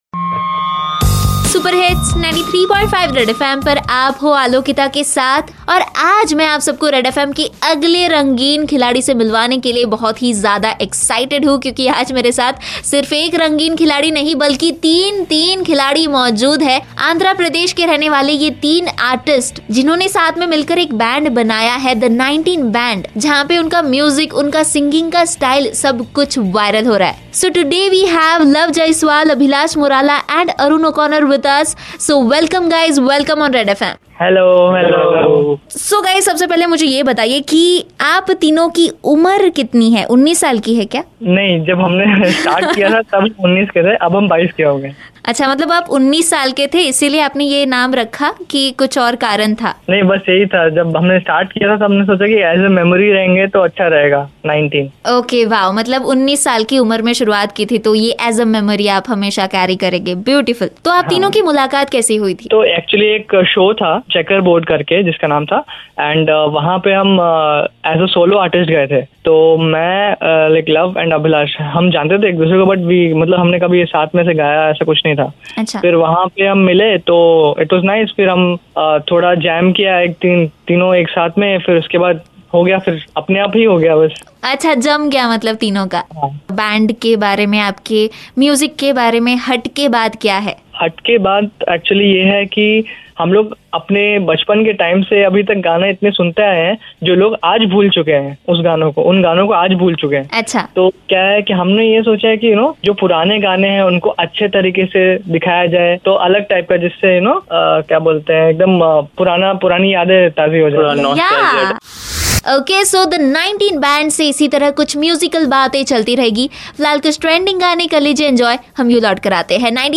Listen to some fun songs in their style.